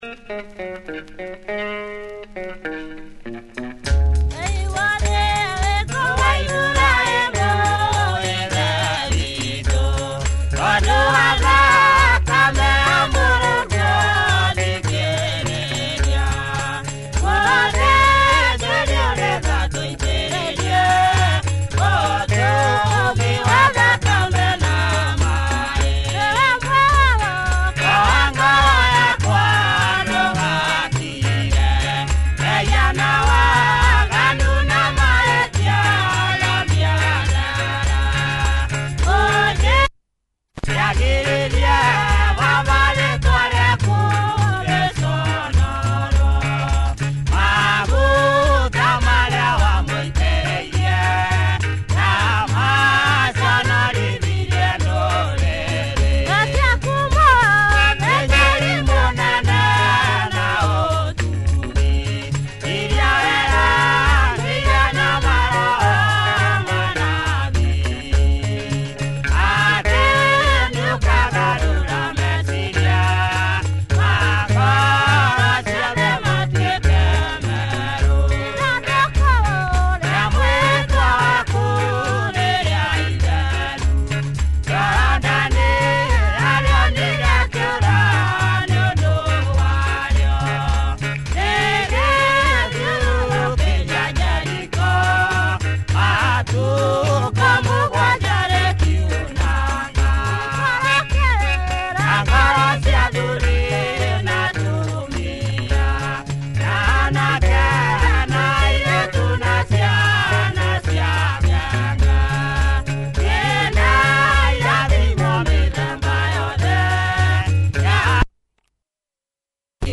Cool rural groove here in typical kikuyu style